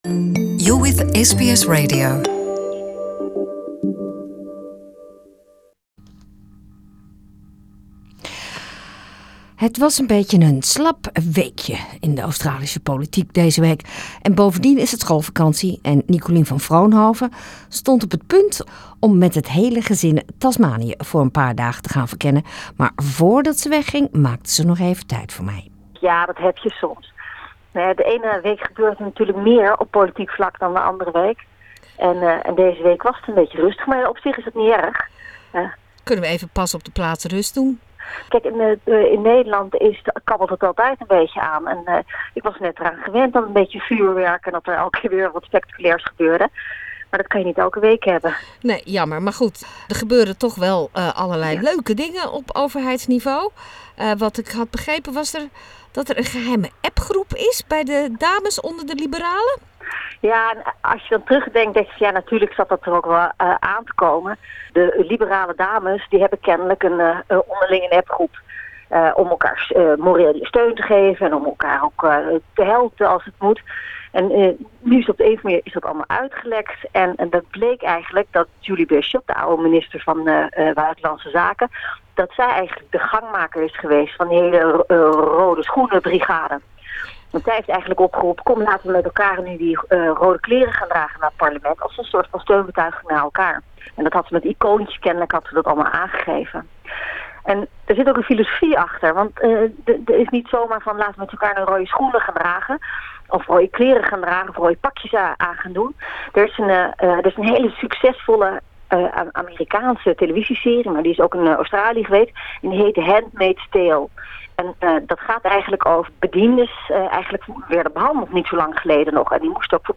Special reporter for Political Affairs, Dutch ex-parliamentarian Nicolien van Vroonhoven, talks about the Handmaid's Tale, red shoes for the political Liberal women, journalistic independence and footy fever!